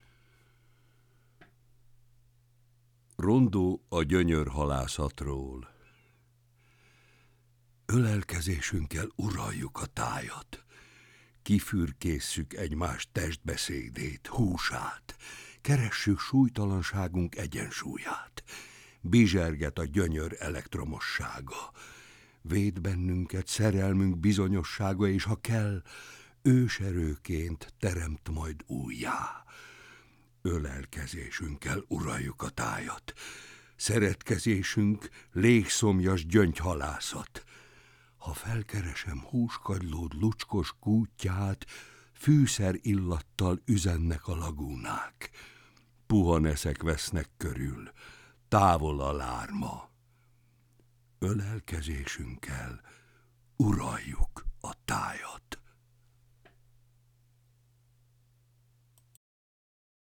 *Versolvasó: Barbinek Péter